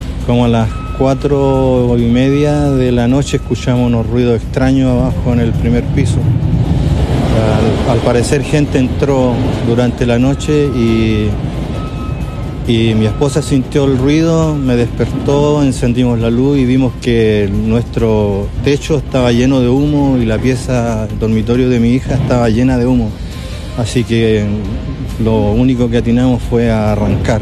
En este sentido, uno de los damnificados contó a Radio Bío Bío el momento en que se enteraron del incendio y los minutos posteriores.